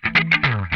CRUNCHSWOOP1.wav